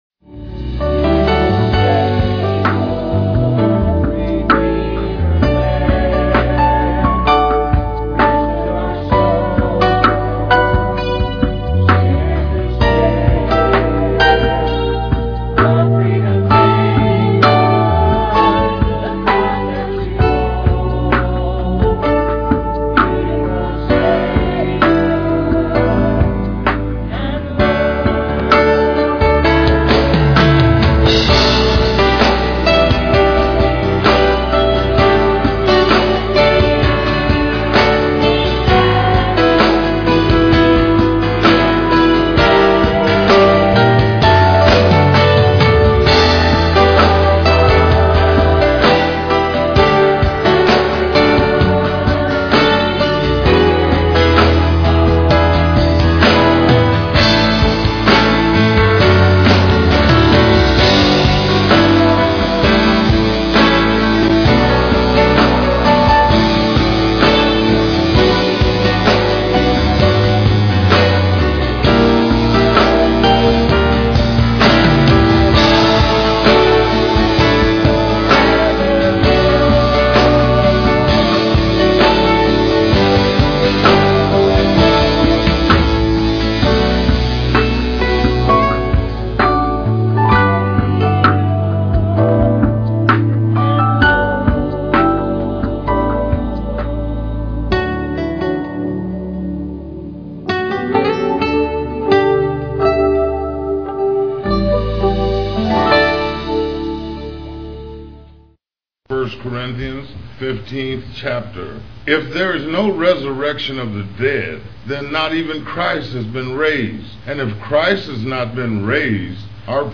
Sung by ABC Choir.